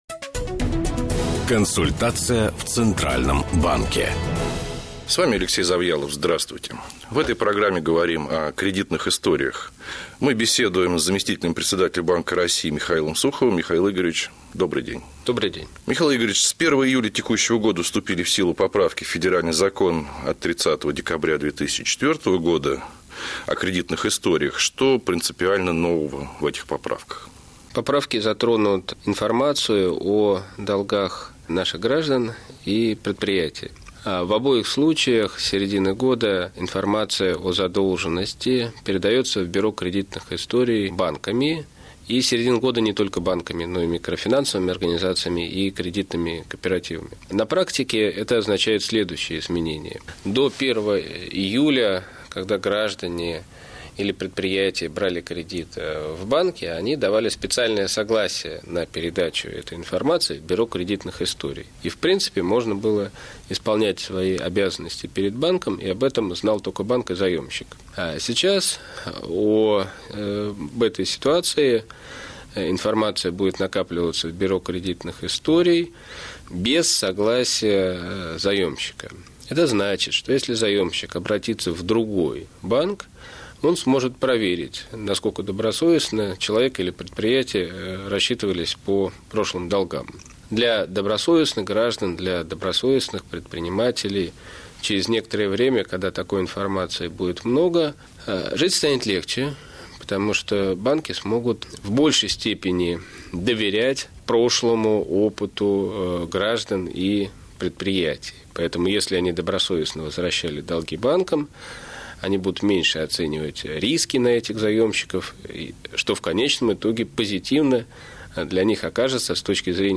Интервью
Интервью заместителя Председателя Банка России М.И. Сухова радиостанции «Бизнес ФМ» 2 октября 2014 года (часть 1)